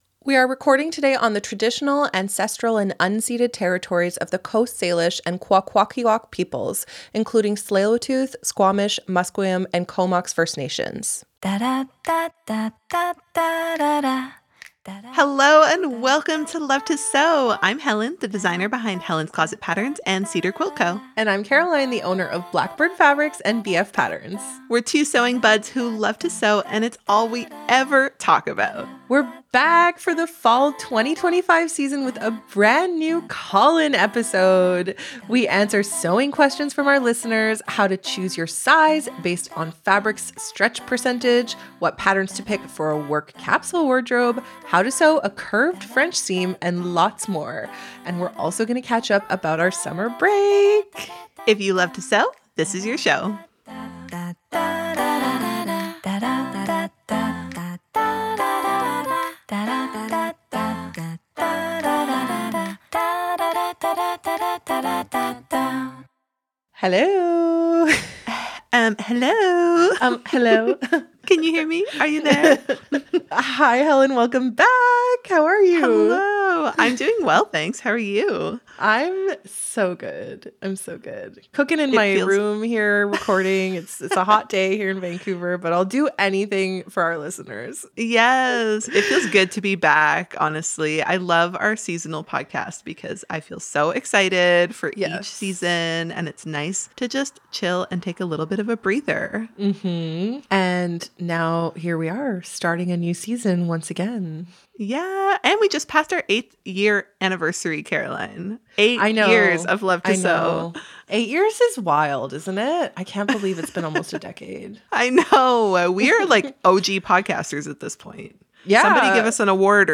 We’re back for the fall 2025 season with a brand-new call-in episode! We answer sewing questions from our listeners: how to choose your size based on your fabric’s stretch percentage, what patterns to pick for a work capsule wardrobe, how to sew a curved French seam, and lots more! We also catch up about our summer break.